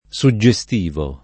suggestivo [ S u JJ e S t & vo ] agg.